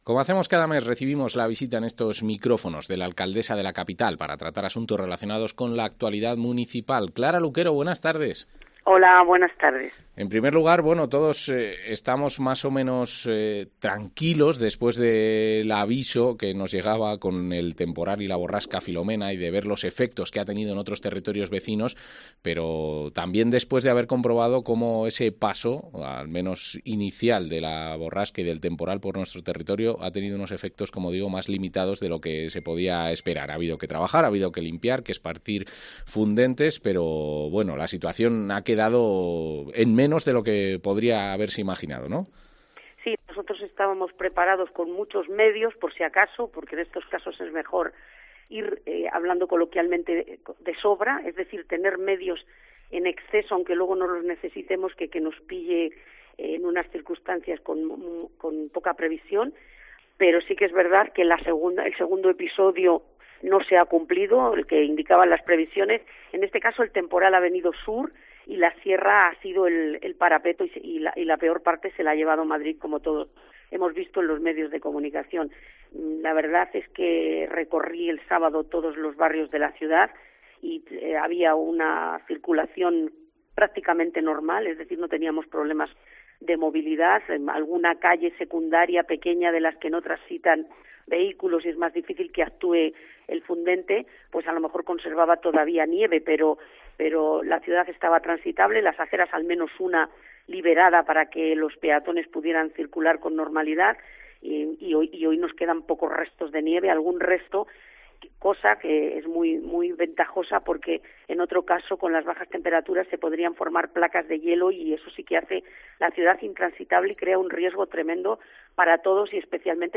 Entrevista a la alcaldesa de Segovia, Clara Luquero